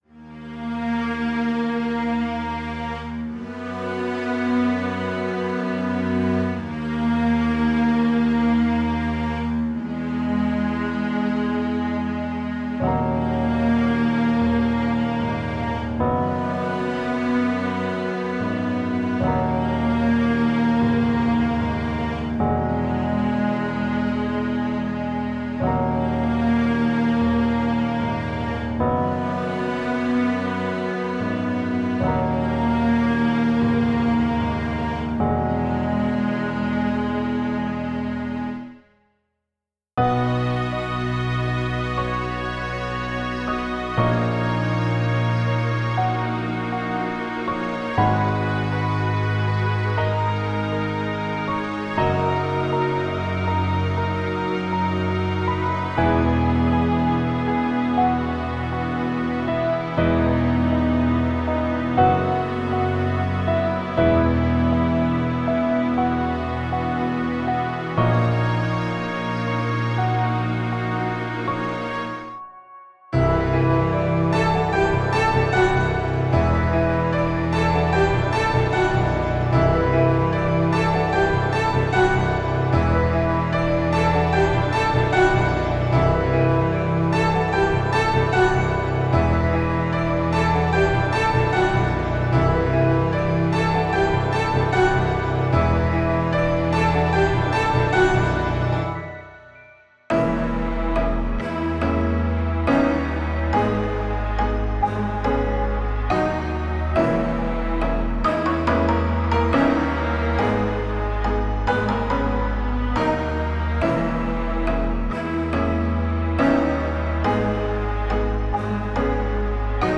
Ambient Cinematic / FX
- 50 Cinematic Construction Kits